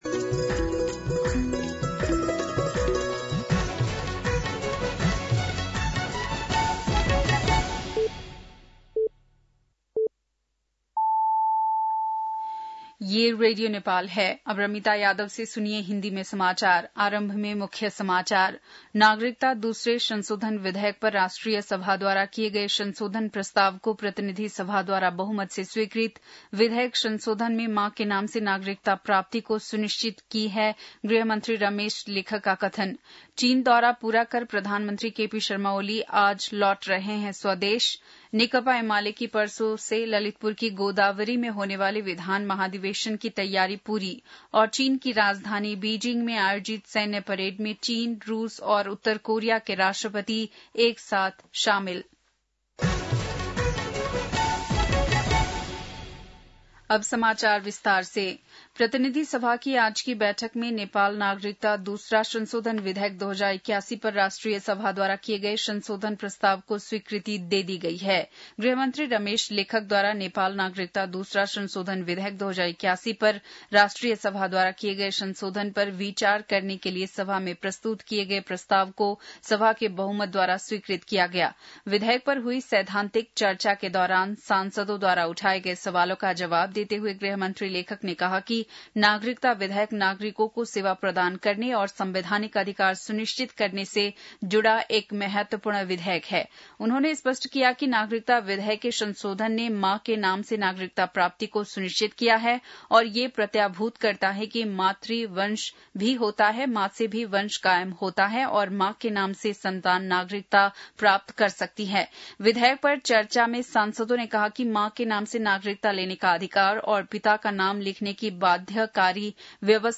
बेलुकी १० बजेको हिन्दी समाचार : १८ भदौ , २०८२